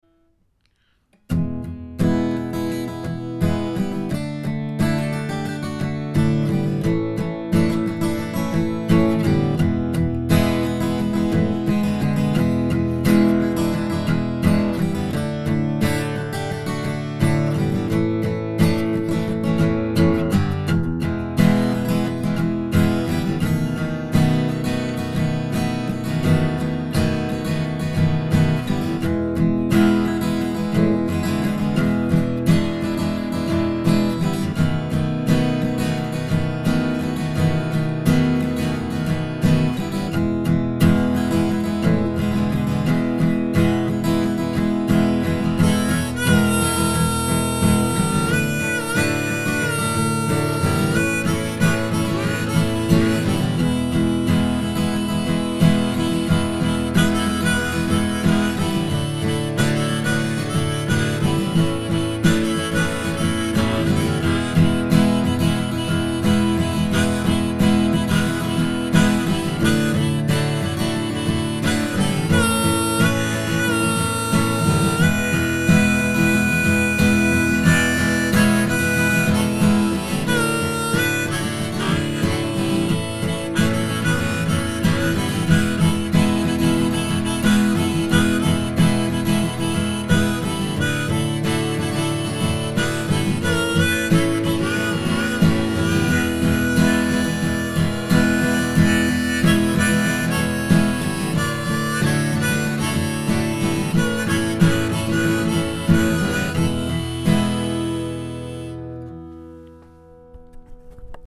Cet a-m quartier libre aussi j'ai enregistré quelques bouts de musique avec ma D-35 récente.
Pas vraiment satisfait du son de l'enregistrement (c'est brut, c'est muddy, je ne sais pas mixer :cry: ), quelques pains et faiblesses rythmiques, mais bon pour donner un aperçu autant de la D-35 que d'un harmonica Lee Oskar (le tout enregistré ensemble, j'ai pas triché ! :lol: )
La D-35 se prête merveilleusement bien à ce jeu bluesy.